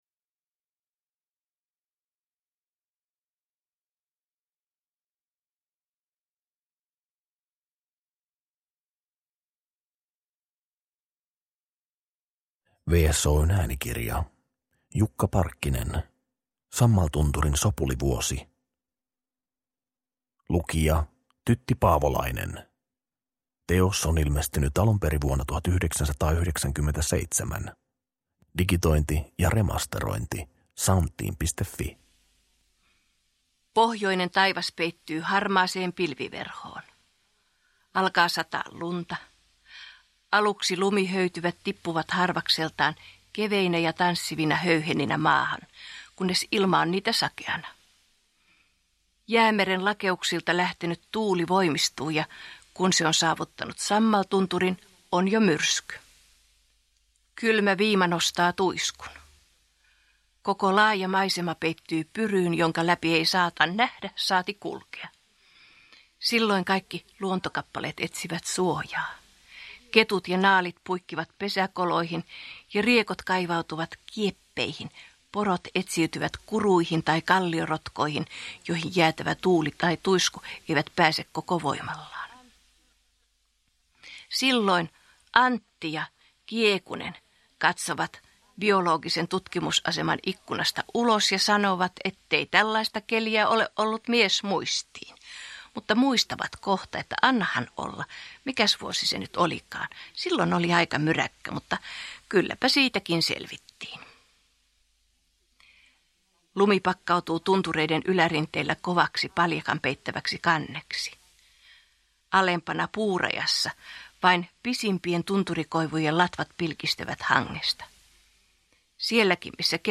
Hilpeä ja hurmaava luontosatu äänikirjana!